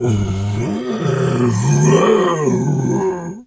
bear_roar_05.wav